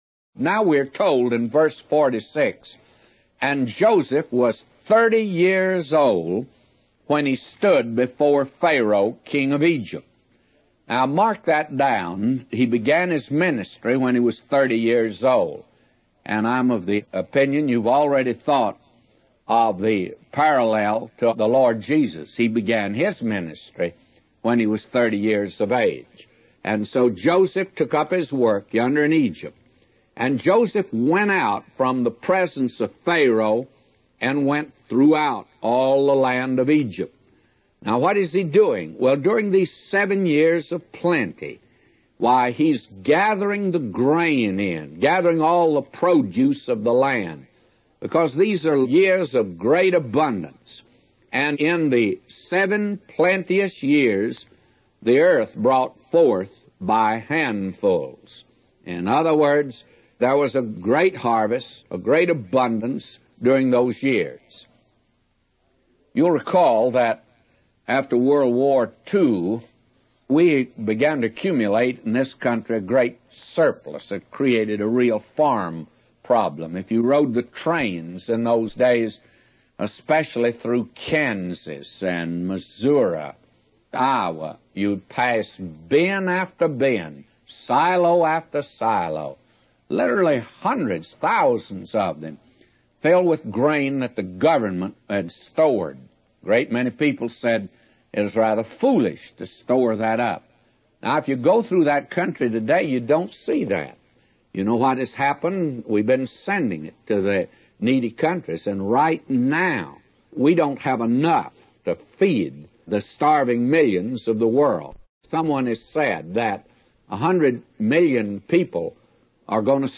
A Commentary By J Vernon MCgee For Genesis 41:46-999